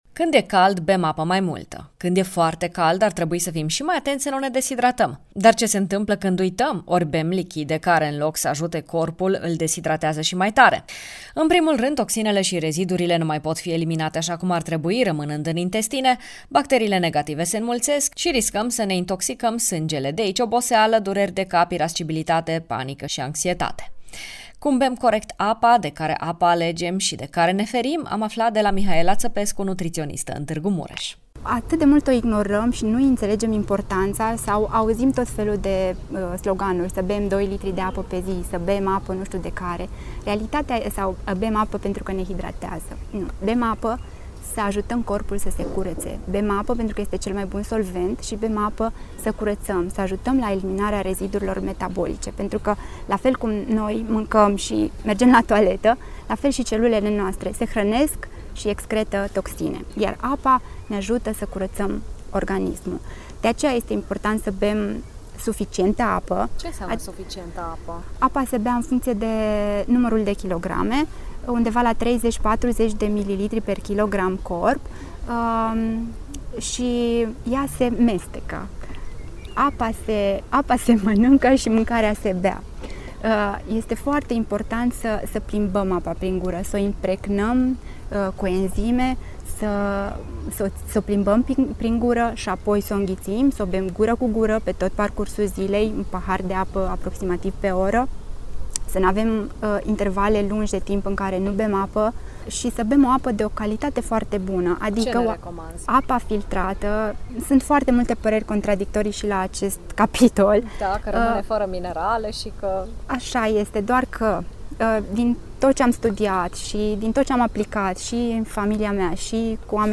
nutritionista